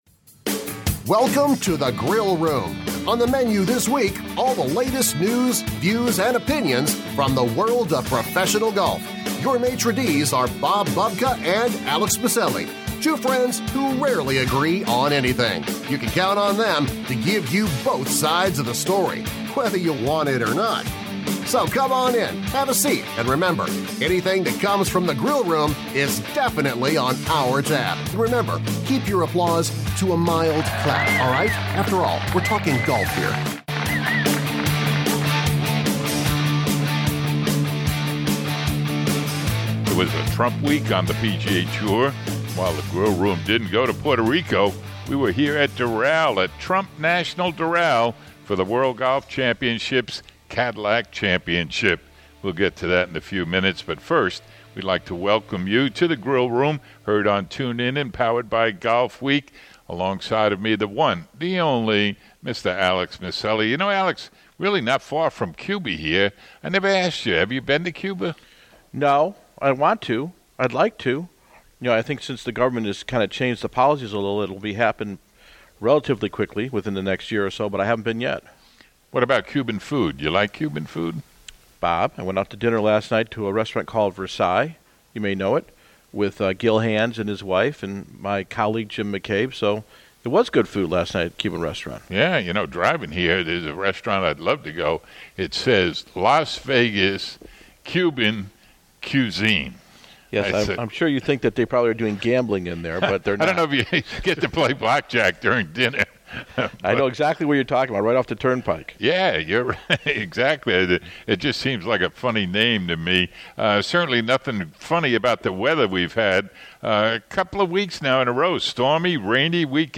Feature Interviews with legendary golf writer Dan Jenkins